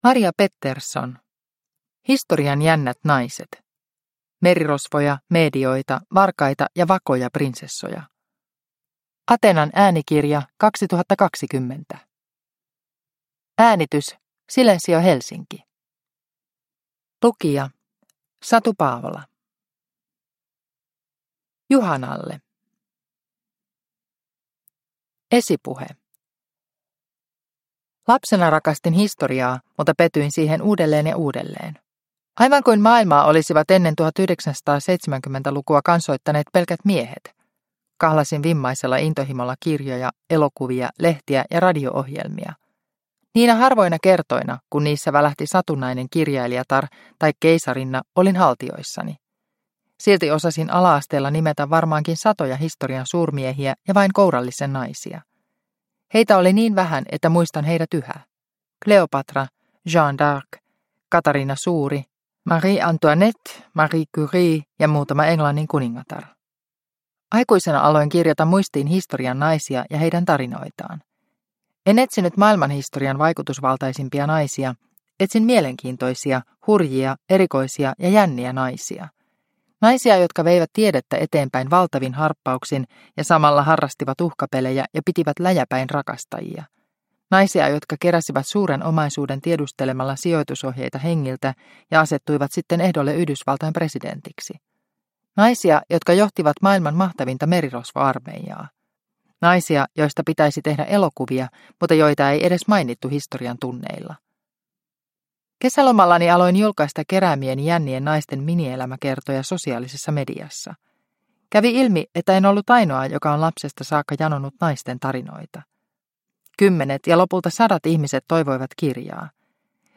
Historian jännät naiset – Ljudbok – Laddas ner